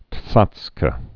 (tsätskə)